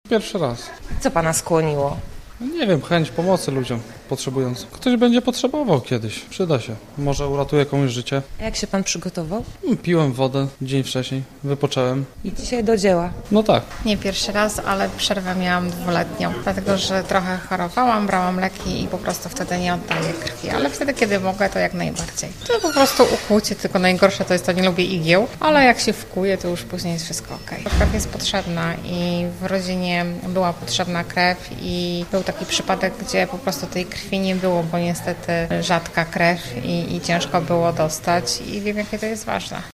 Dzisiaj w gorzowskim punkcie poboru spotkaliśmy sporo osób. Jak nam powiedzieli, oddają krew, bo to po prostu ratuje komuś życie.